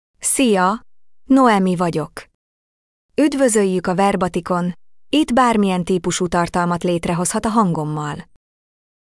Noemi — Female Hungarian (Hungary) AI Voice | TTS, Voice Cloning & Video | Verbatik AI
NoemiFemale Hungarian AI voice
Noemi is a female AI voice for Hungarian (Hungary).
Voice sample
Listen to Noemi's female Hungarian voice.
Noemi delivers clear pronunciation with authentic Hungary Hungarian intonation, making your content sound professionally produced.